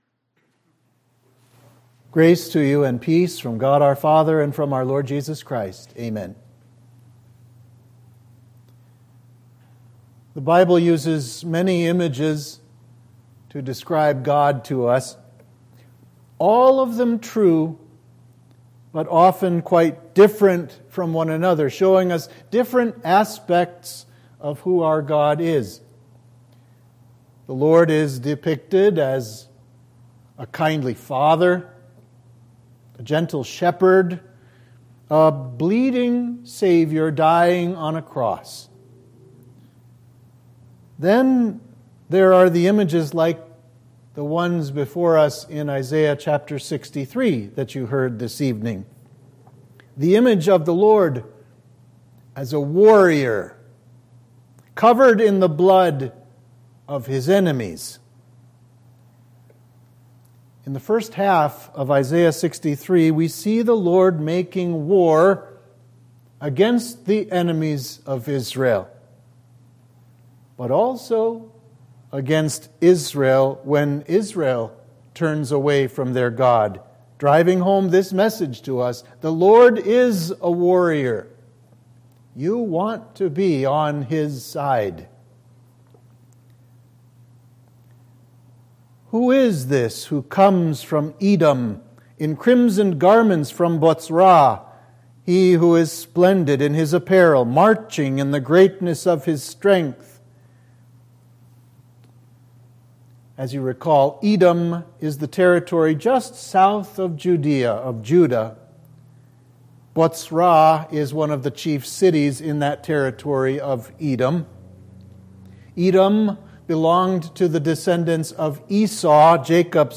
Sermon for Midweek of Trinity 21